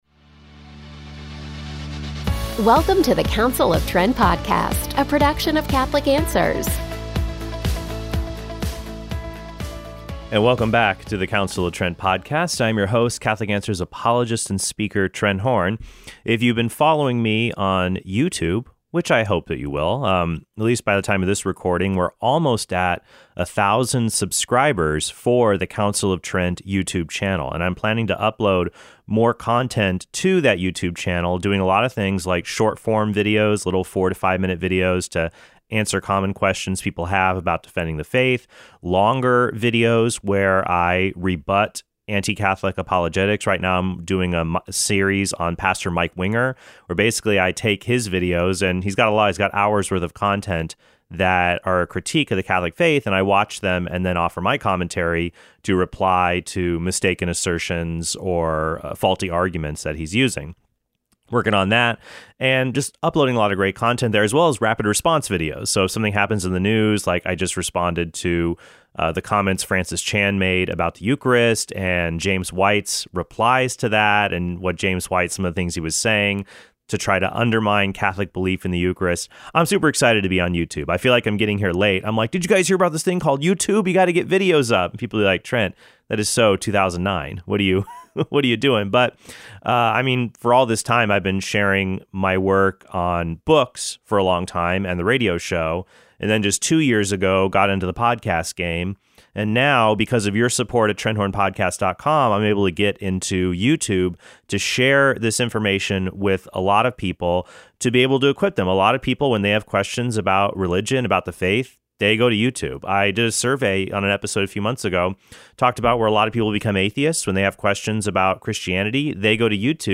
includes sound bites from scientists like Lawrence Krauss, Sean Carroll, and Max Tegmark